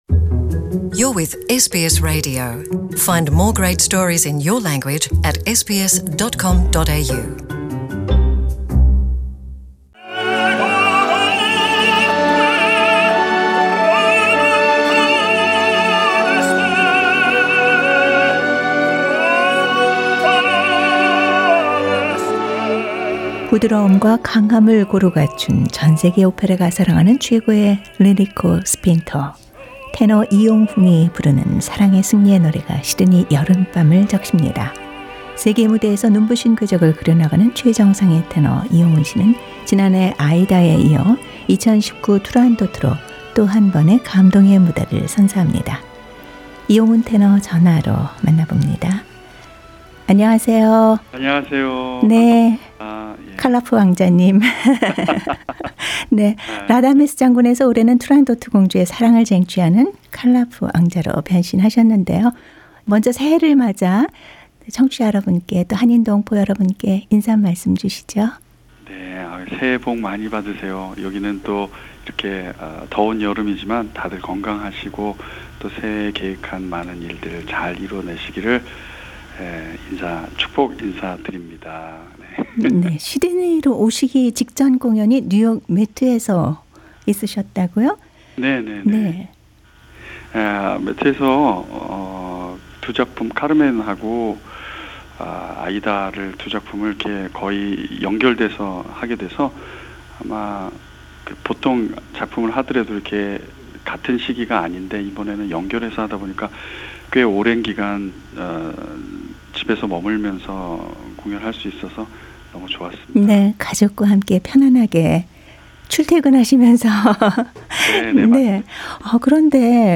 Full interview with Yonghoon Lee is available on podcast.